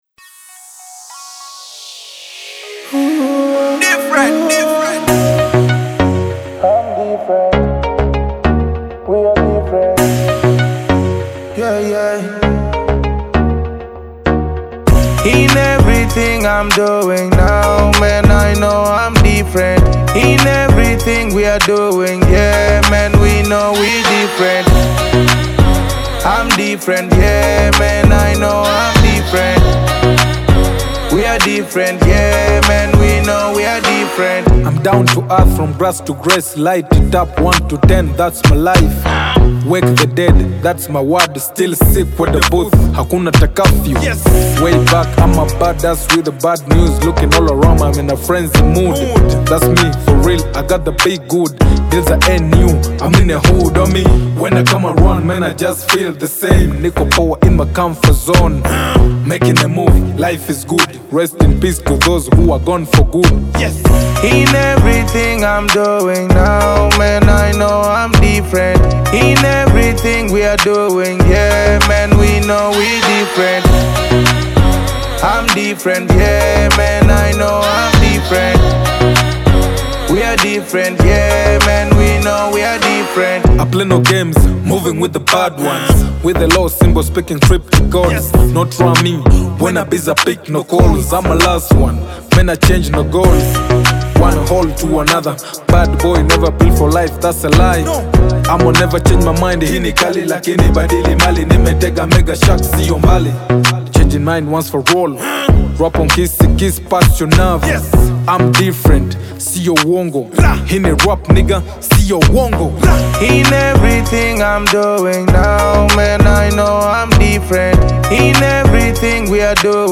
Genre: Rap Music